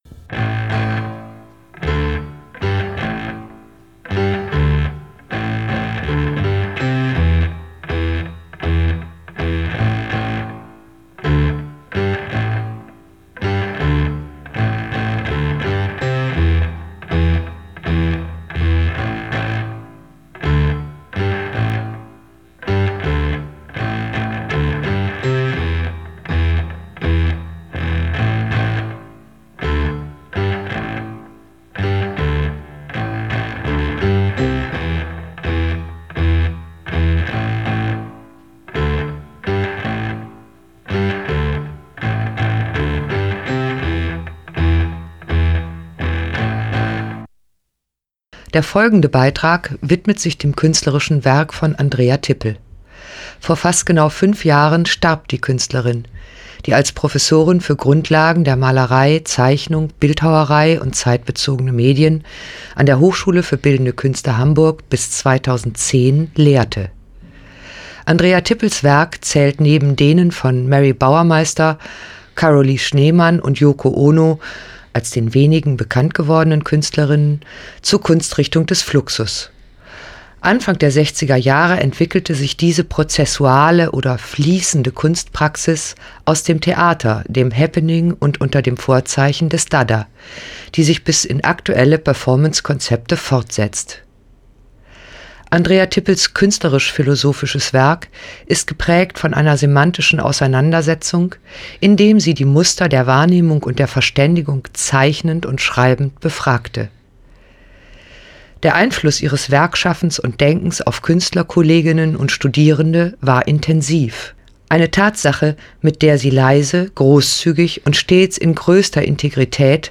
Radiobeitrag, gesendet am 13.04.2018 bei agoRadio, Beiträge zu Kultur und Politik: „Freispiel“.